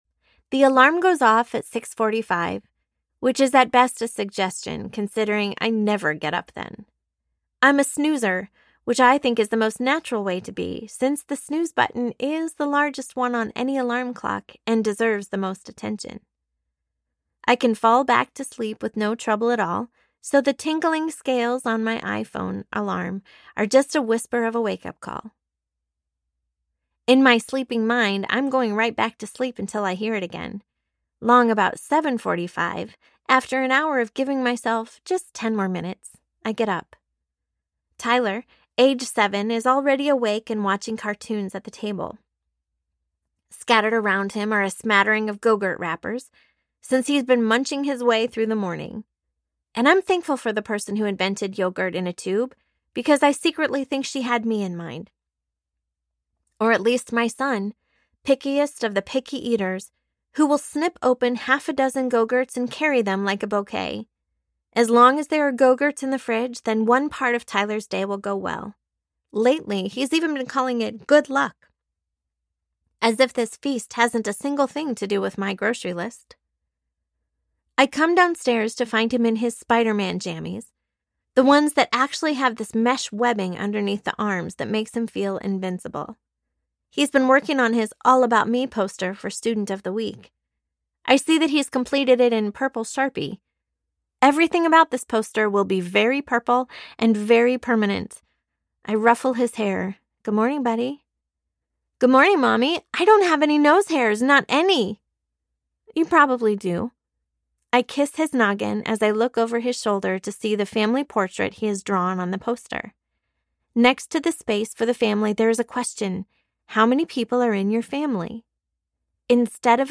Let’s Pretend We’re Normal Audiobook